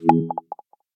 menu-options-click.ogg